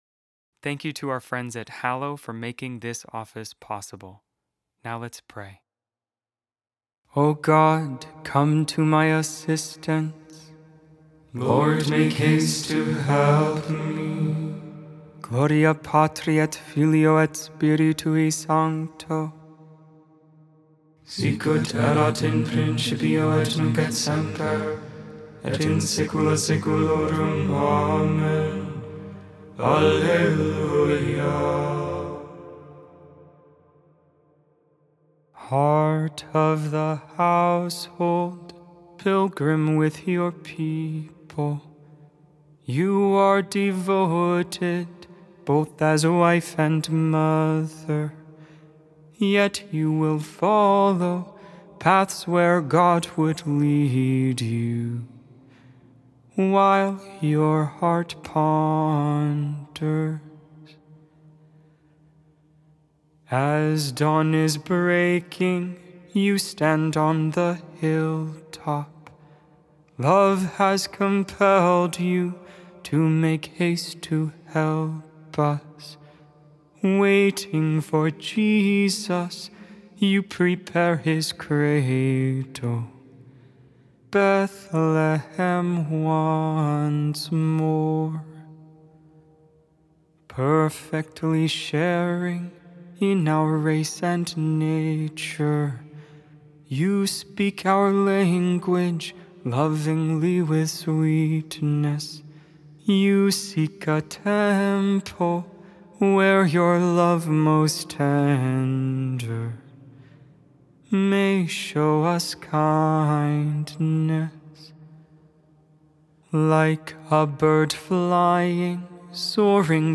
Lauds, Morning Prayer for the 2nd Tuesday in Advent, December 12, 2023.Feast of Our Lady of Guadalupe.Made without AI. 100% human vocals, 100% real prayer.